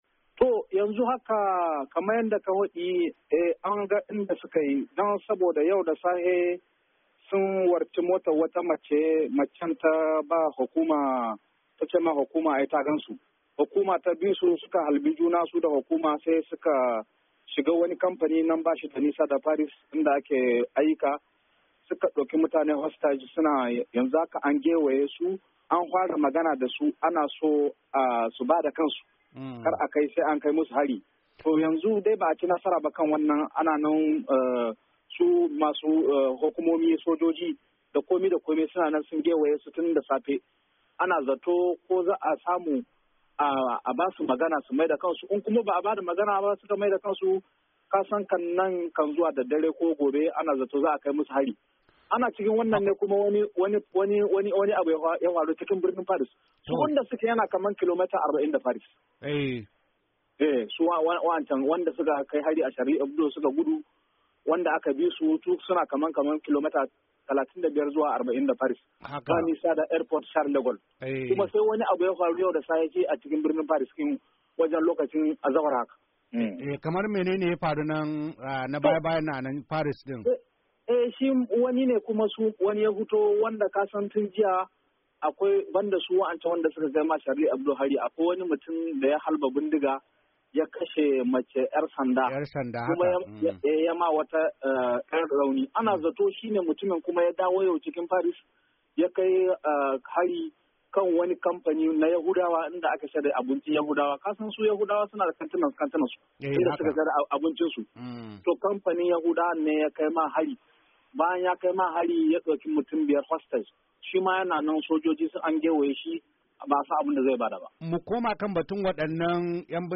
Dandalin VOA ya tattauna da wani dan jarida dake zaune a birnin Paris